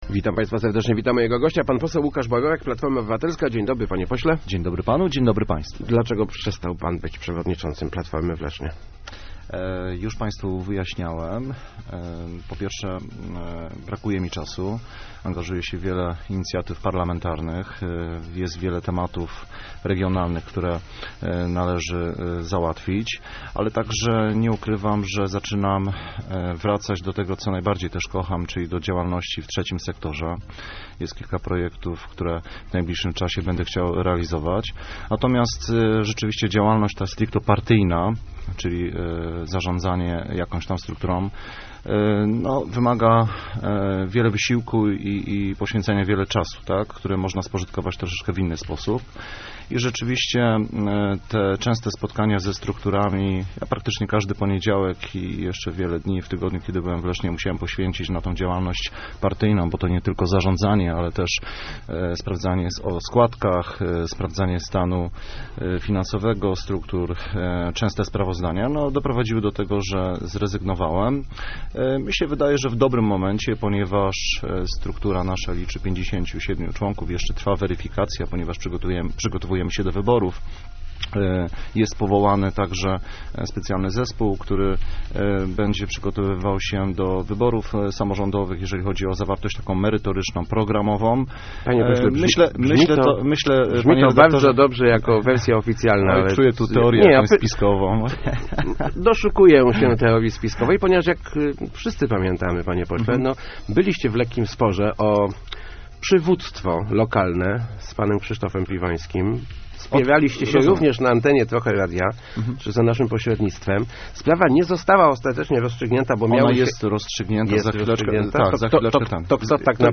Sprawę rozłączenia struktur miejskich i powiatowych PO odłożyliśmy na czas po wyborach w partii - powiedział w Rozmowach Elki poseł Łukasz Borowiak. Potwierdził, że z kierowania kołem miejskim zrezygnował z powodu zbytniego natłoku obowiązków.